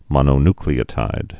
(mŏnō-nklē-ə-tīd, -ny-)